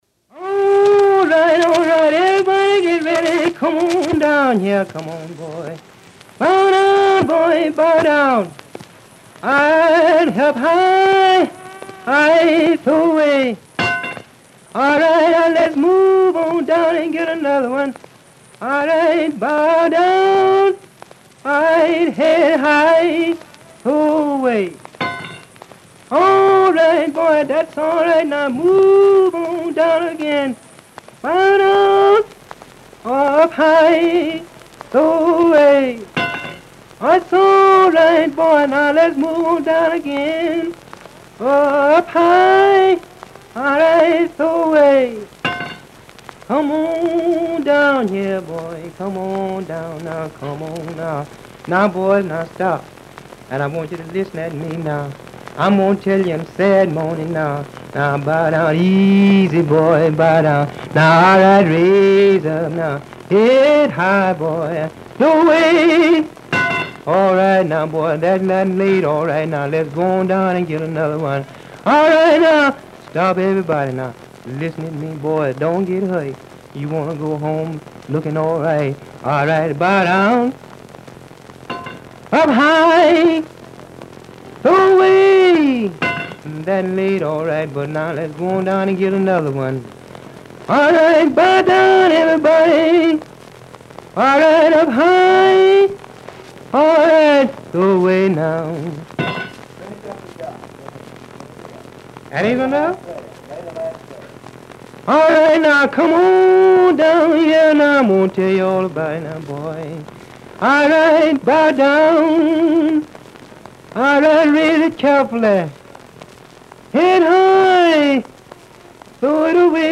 Field hollers